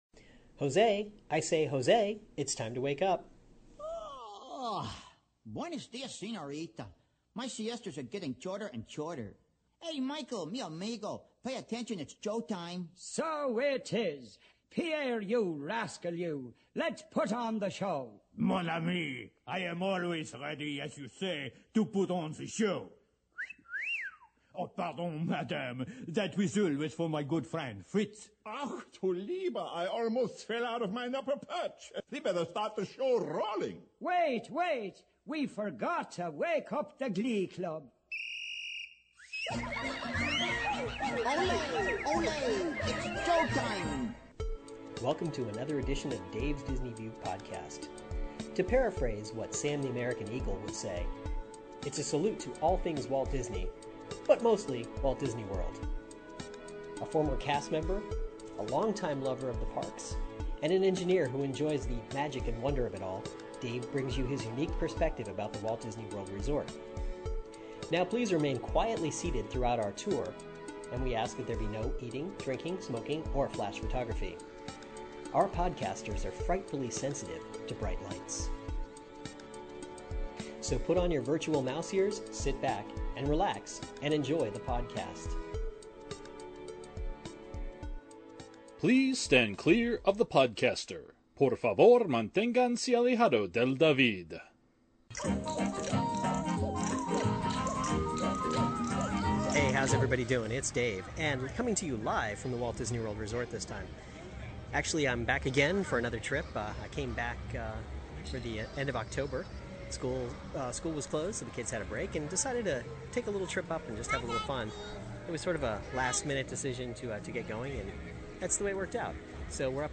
Follow me along on my adventures as I tide a few attractions and talk about the parks.